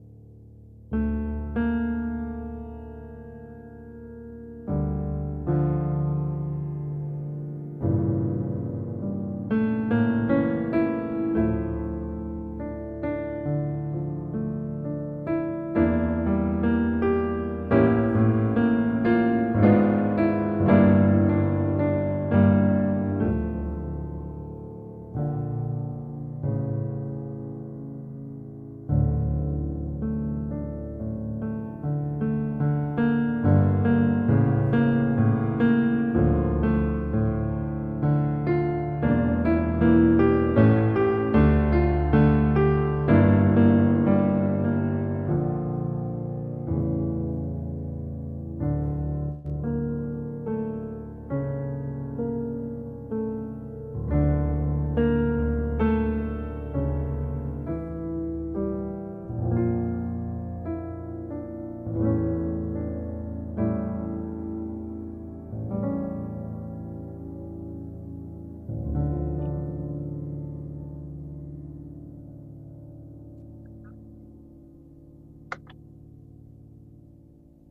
Da un punto di vista musicale, la tristezza ha una qualità più scura e più spiacevole.
C’è discesa melodica, ci sono colori scuri, ci sono tensioni.